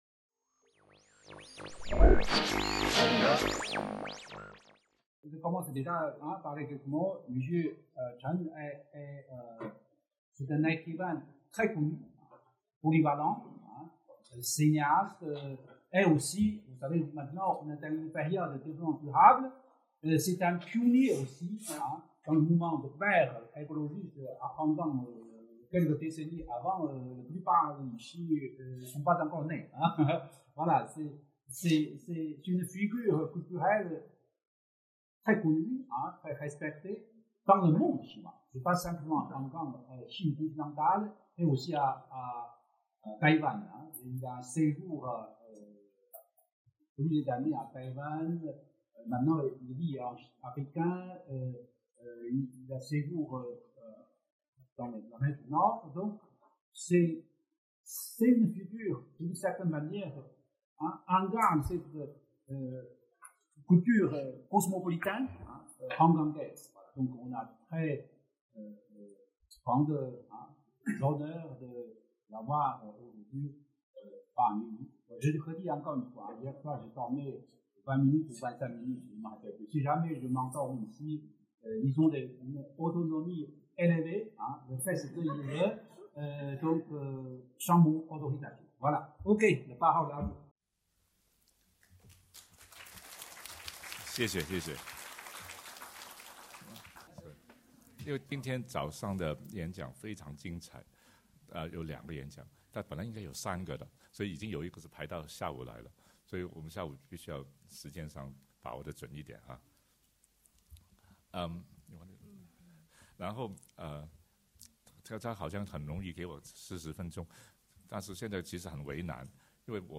Colloque Hong Kong : protestations, politique et identité Télécharger le programme (PDF) Regarder Hong Kong de l’intérieur : Hybrité, Nativité et Subjectivité par Chan Koon Chung (écrivain et essayiste)